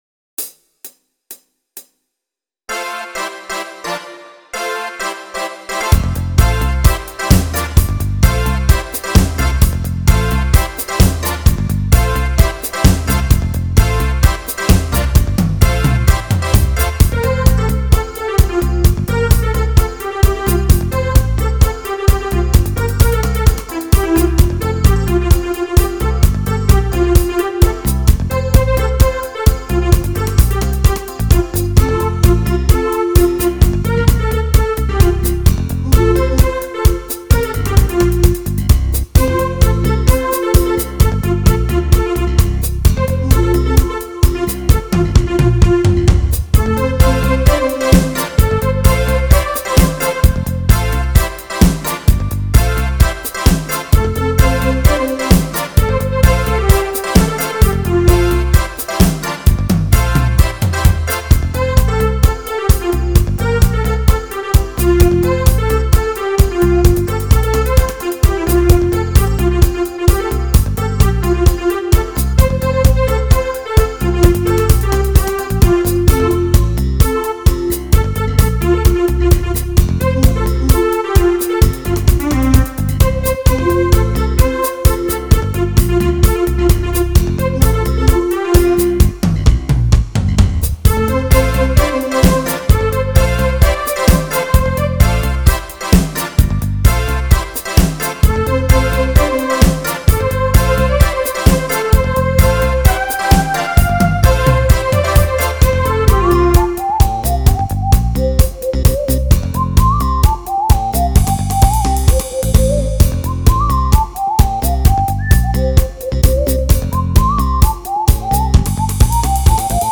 thGM2Y2HRK  Download Instrumental